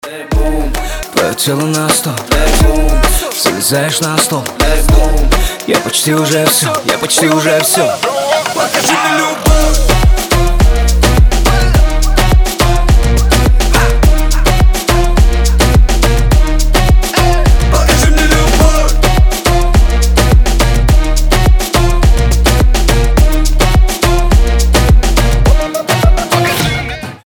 • Качество: 320, Stereo
зажигательные
заводные
dancehall
Moombahton